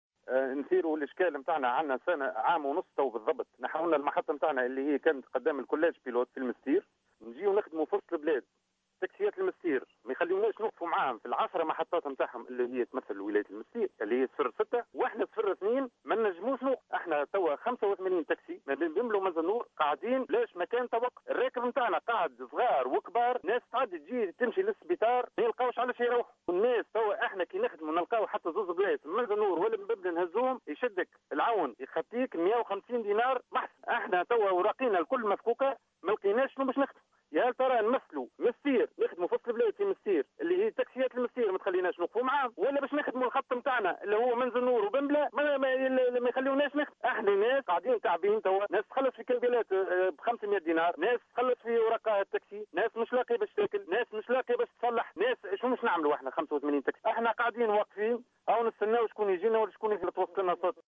أحد سواق التاكسي